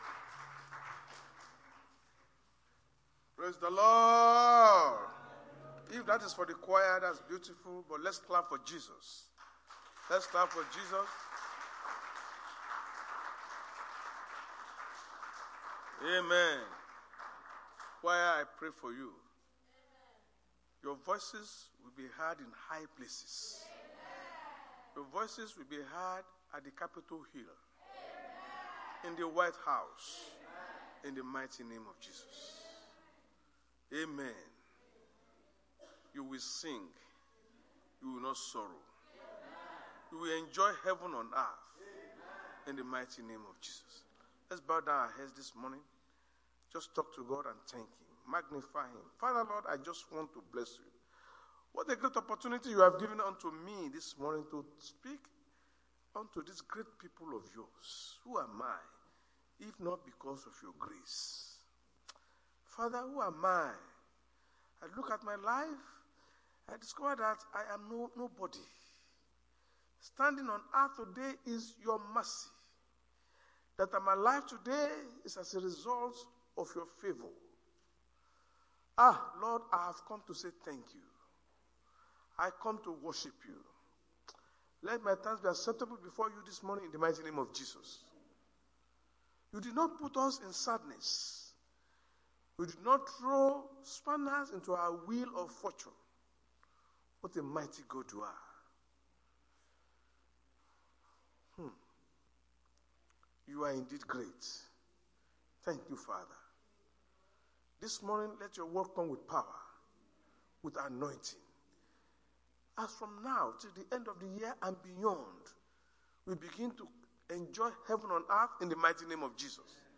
Sunday Sermon: Heaven On Earth
Service Type: Sunday Church Service